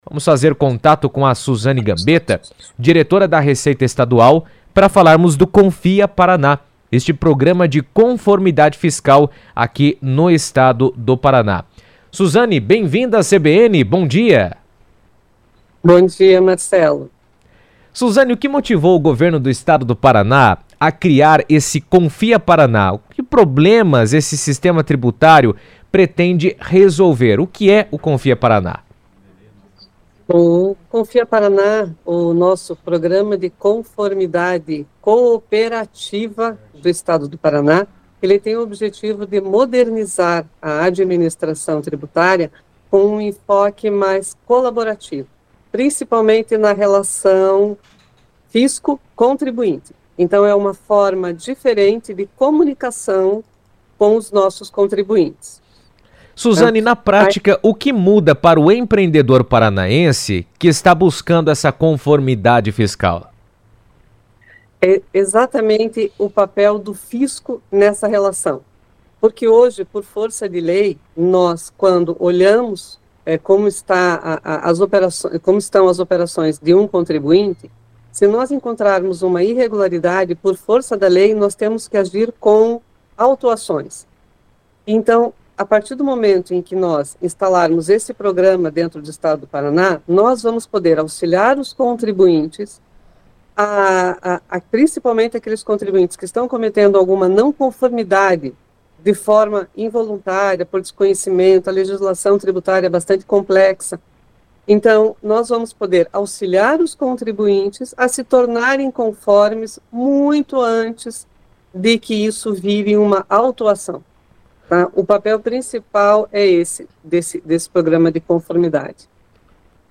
O Governo do Estado regulamentou o Confia Paraná, programa de conformidade fiscal cooperativa que busca fortalecer a transparência e a relação de confiança entre contribuintes e administração tributária. Em entrevista à CBN, a diretora da Receita Estadual, Suzane Gambetta, destacou que a iniciativa representa um avanço na modernização da gestão fiscal e no estímulo à regularidade tributária no Paraná.